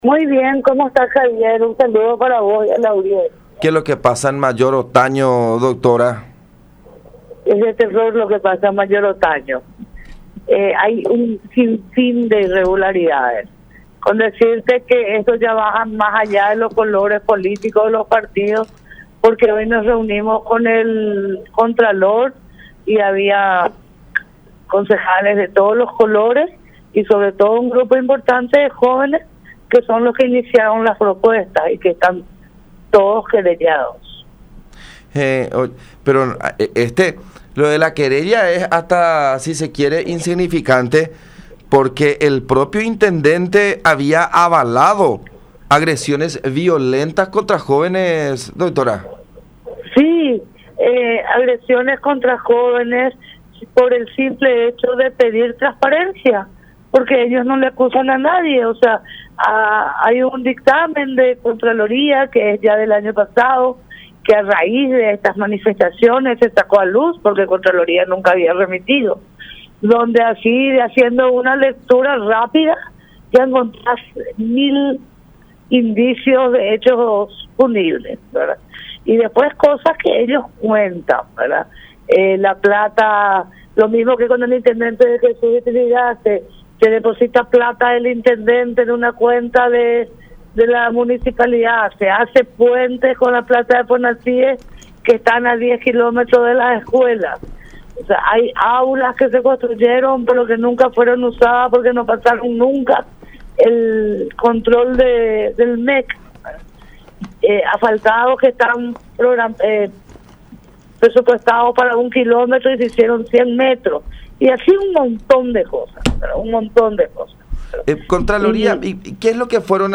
en comunicación con La Unión sobre denuncias de corrupción en la Municipalidad de Mayor Otaño.